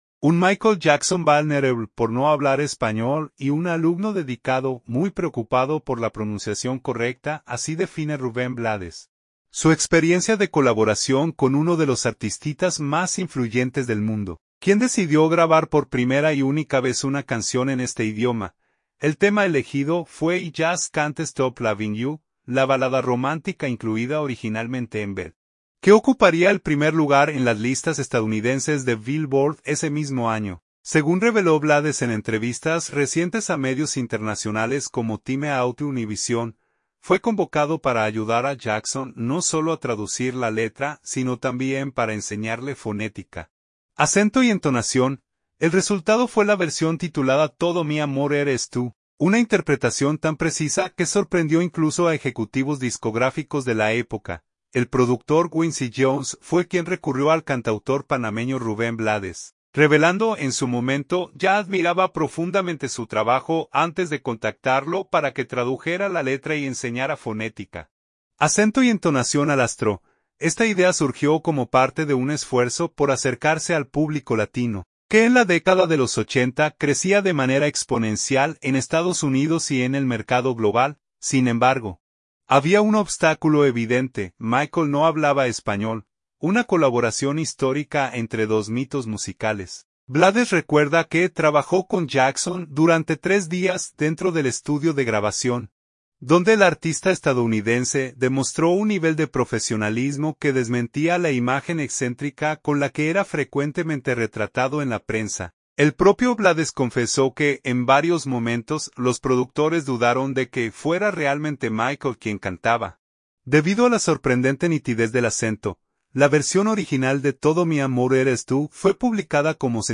la balada romántica
dentro del estudio de grabación